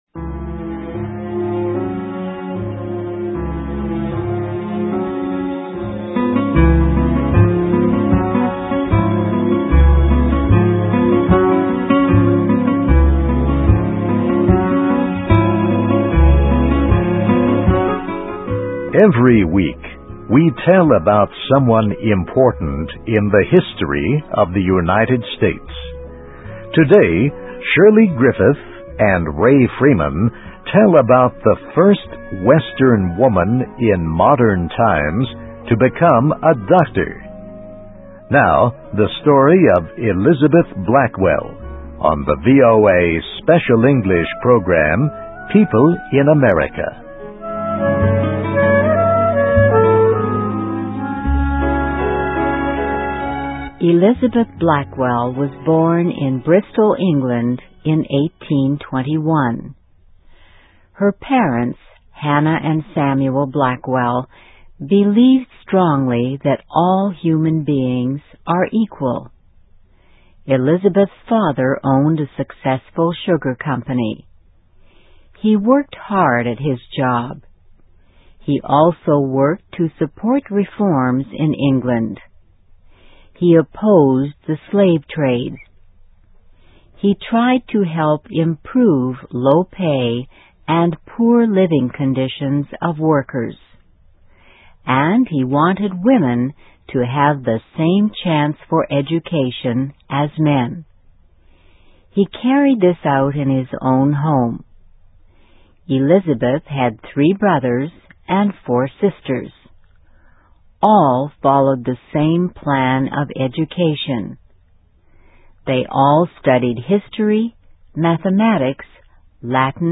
Biography - Elizabeth Blackwell, 1821-1910: Against Strong Opposition, She Became the First Western Woman in Modern Times to Become a Doctor (VOA Special English 2007-07-21)
Listen and Read Along - Text with Audio - For ESL Students - For Learning English